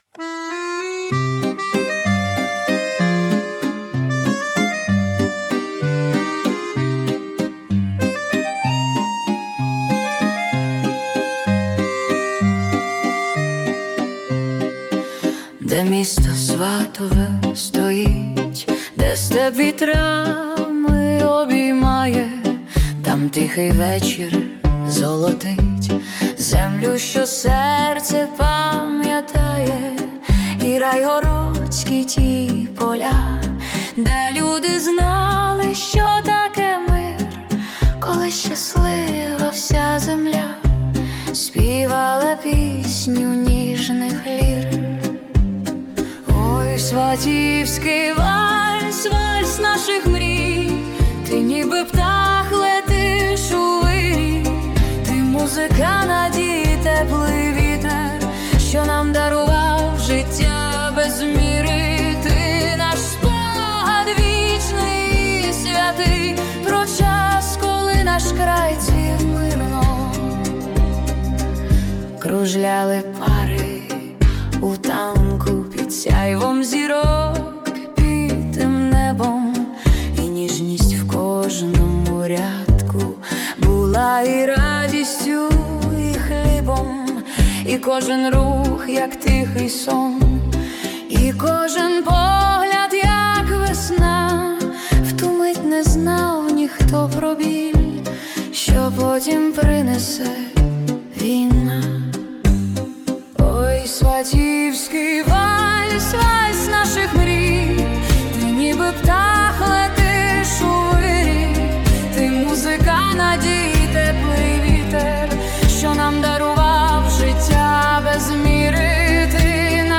🎵 Жанр: Lyrical Waltz